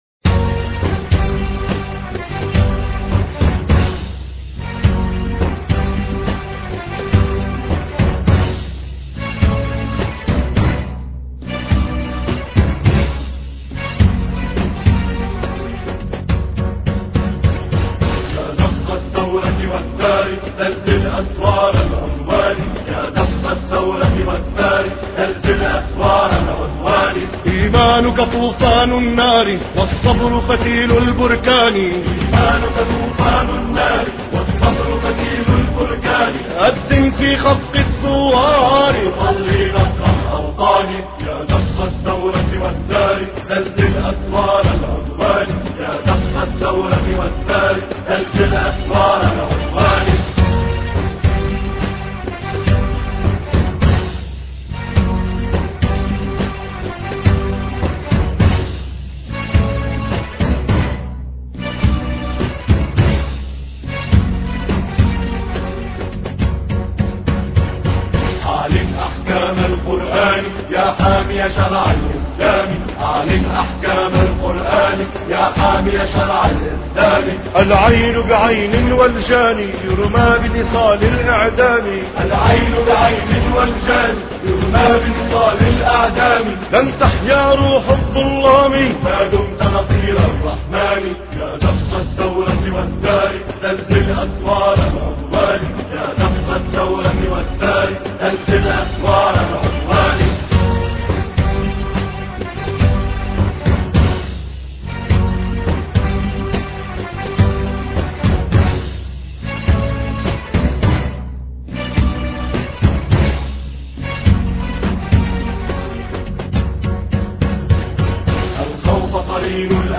زلزل أسوار العدوان الثلاثاء 6 فبراير 2007 - 00:00 بتوقيت طهران تنزيل الحماسية شاركوا هذا الخبر مع أصدقائكم ذات صلة الاقصى شد الرحلة أيها السائل عني من أنا..